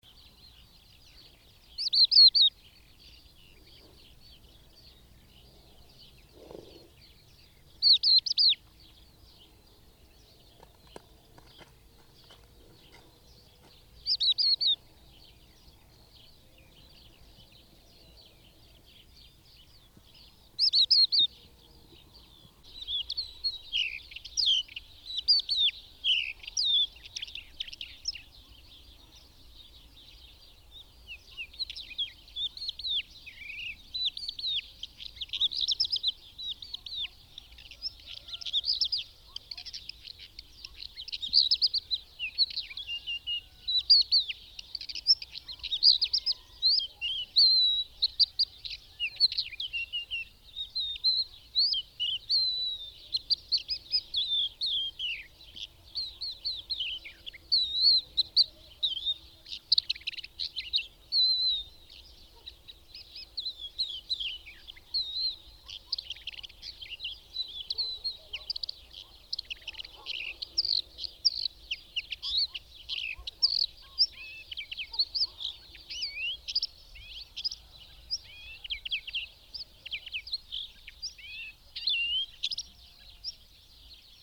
Ciocarlia-3-Ciocarlan-Galerida-cristata.mp3